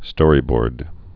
(stôrē-bôrd)